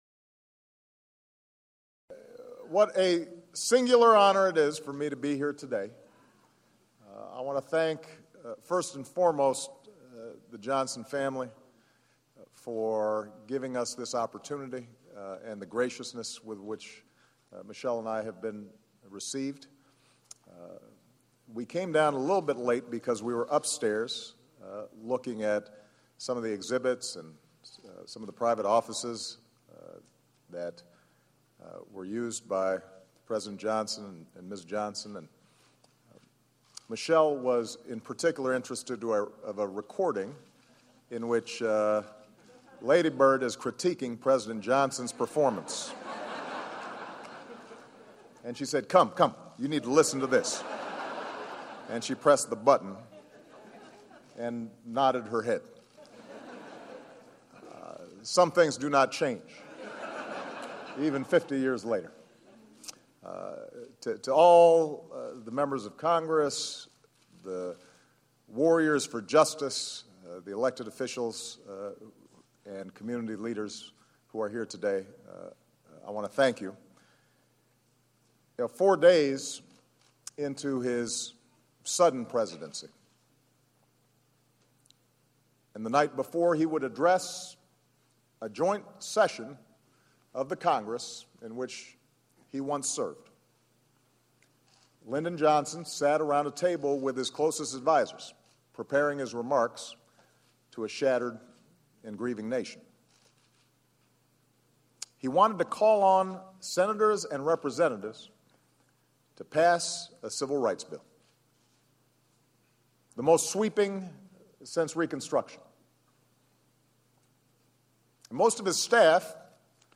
U.S. President Barack Obama delivers the keynote address at the Lyndon B. Johnson Presidential Library Civil Rights Summit